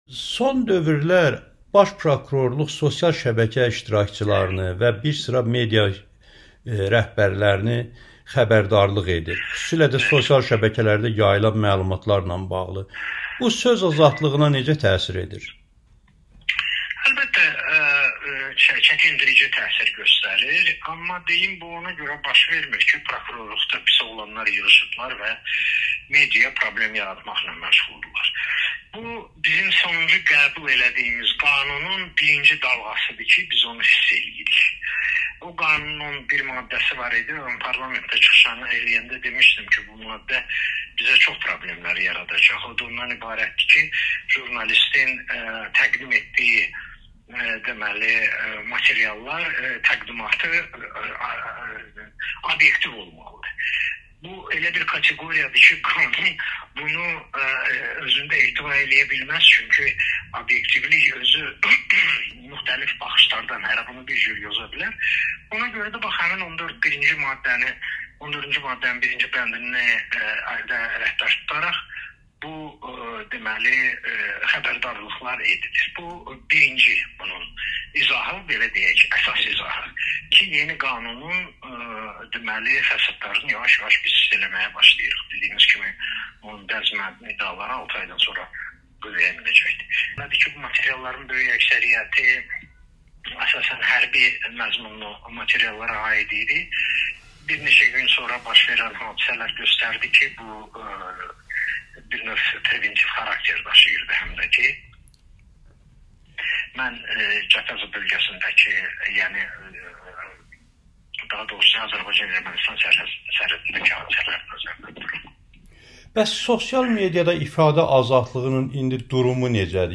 Amerikanın Səsinə müsahibəsində bildirib ki, Baş Prokurorluğun jurnalistlərə və sosial şəbəkə iştirakçılarına xəbrdarlıq etməsi söz azadlığına çəkindirici təsir edir.